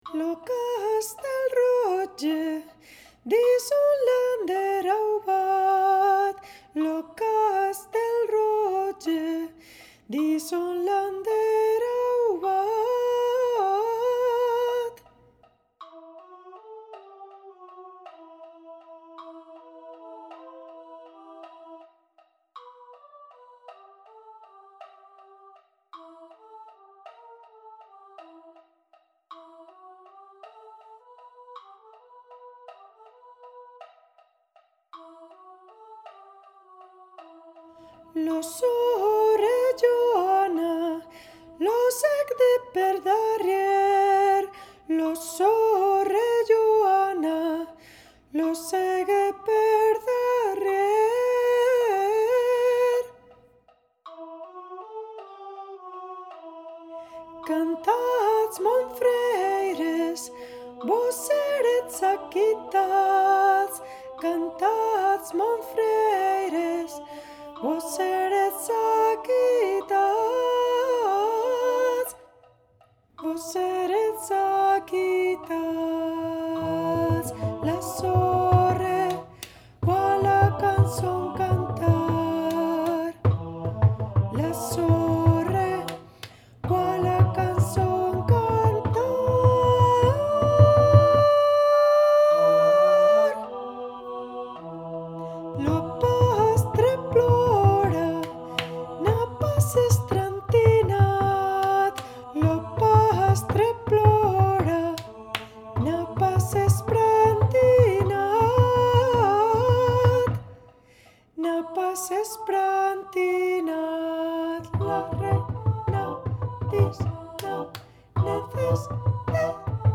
Audio grabado de SOPRANO 1
canción tradicional occitana
en arreglo para coro de voces iguales SMA.